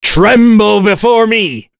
voices / heroes / en
Kain-Vox_Skill2_b.wav